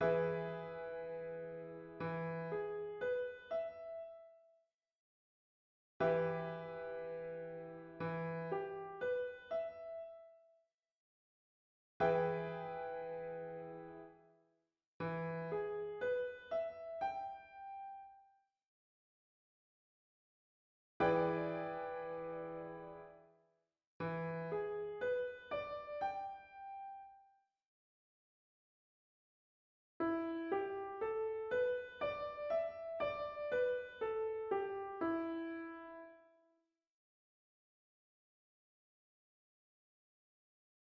Exemples ci-dessous : accords au piano (plaqués puis arpégés). Le dernier exemple fait entendre la gamme pentatonique, gamme du blues par excellence qui se retrouvera par la suite dans la partie de piano.
accords-blues.mp3